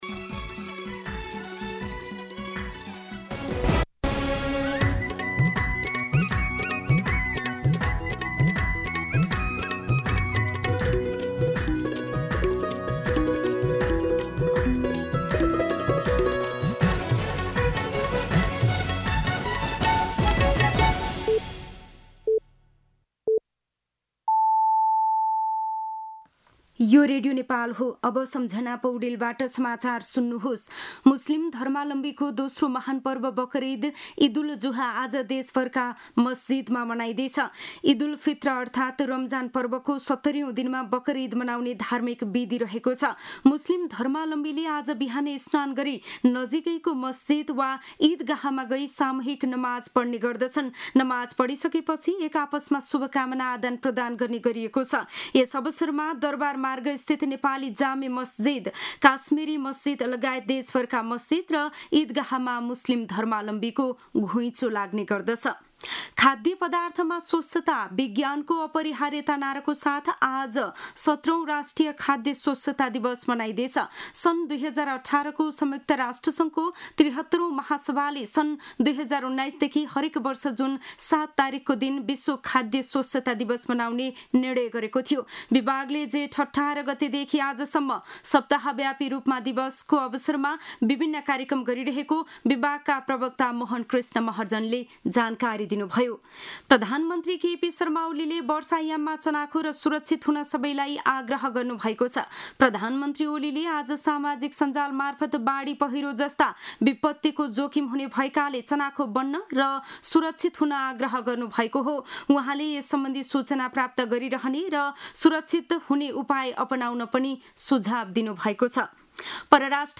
An online outlet of Nepal's national radio broadcaster
मध्यान्ह १२ बजेको नेपाली समाचार : २४ जेठ , २०८२